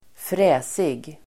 Ladda ner uttalet
fräsig adjektiv (vardagligt; även allmänt tilltalande), classy , swish Uttal: [²fr'ä:sig] Böjningar: fräsigt, fräsiga Synonymer: fräck, häftig, tuff Definition: moderiktig Exempel: en fräsig bil (a classy car)